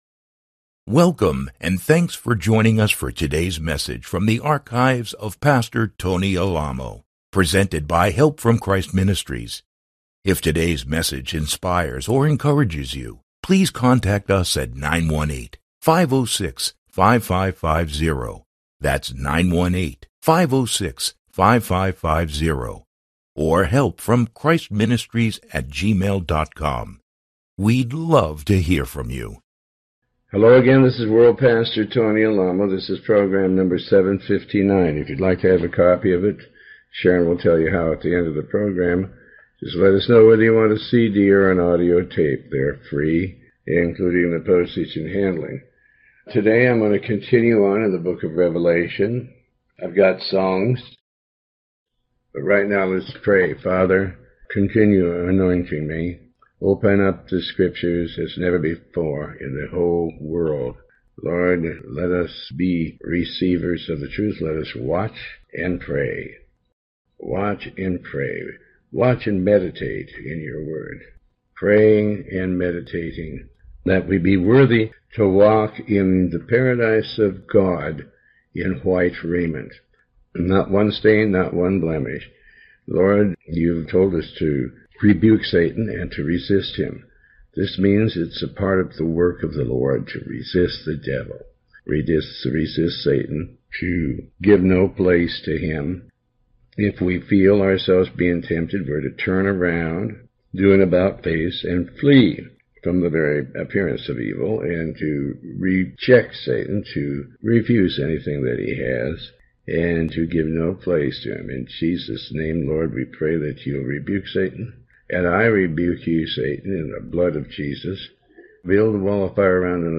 Pastor Tony Alamo Reads from and comments on the Book of Revelation Chapter 3